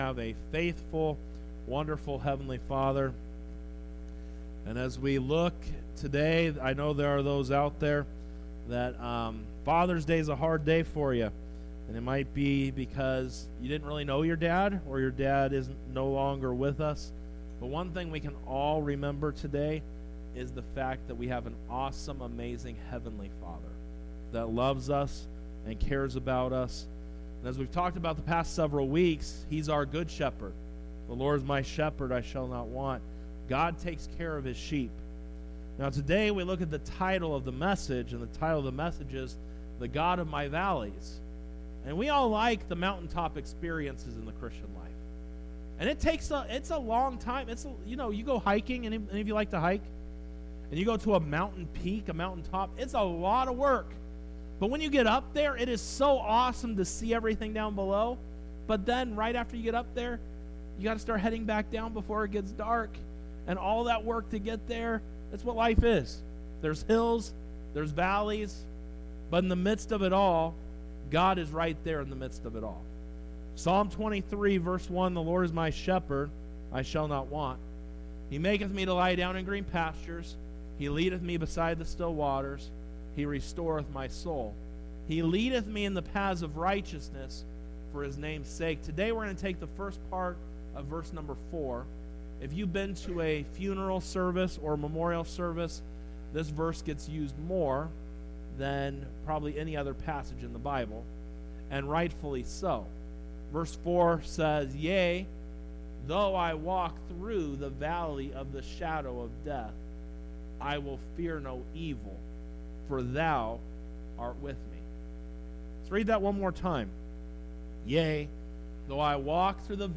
Sermons | Victory Baptist Church
Sunday Worship Service 06:16:24 - The God Of My Valleys